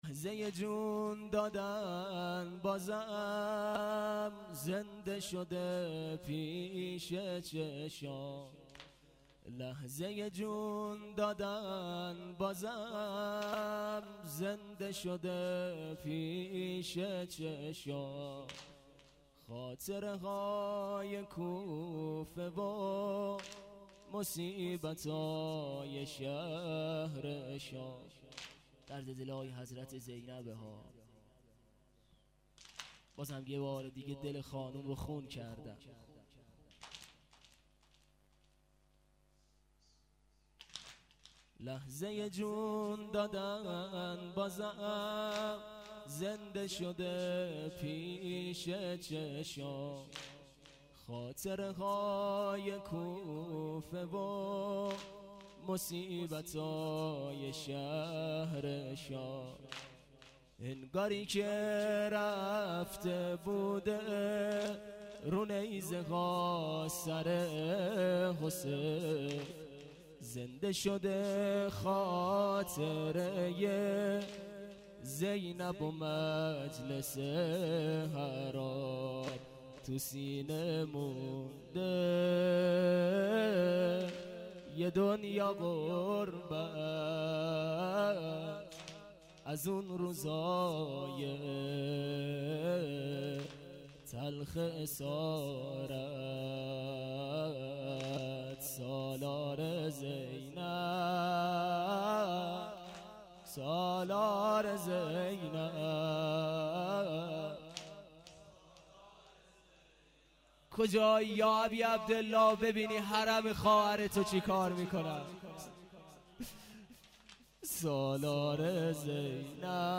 شب یازدهم ماه رمضان 1392- هیات لثارات الحسین(ع) - حوزه علمیه نخبگان